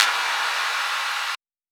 VTDS2 Song Kit 15 Rap U Got Me Rocking FX Hallclap.wav